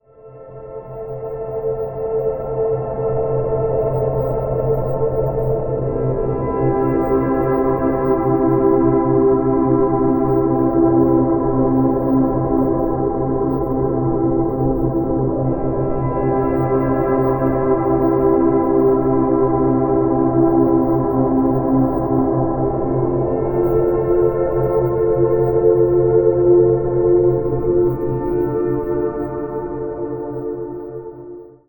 Set to deeply relaxing tones, “Subliminal Deep Sleep Affirmations” uses established science – 3-Hz binaural beats, designed to enhance your brains delta (sleep) activity mixed with subliminal affirmations to automatically reprogram your mind to easily let go of stress and anxiety, replace self-defeating sleep habits with the habits of successful and full rejuvenation and support you in a more restful and rejuvenating sleep deeper than you ever have.
sleep_subliminal_sample.mp3